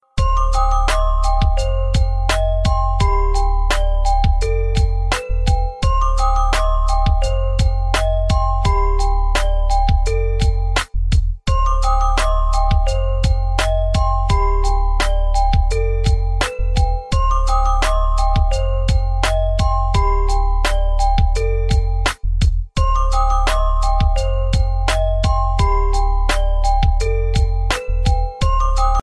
iphone-x-ringtone_14195.mp3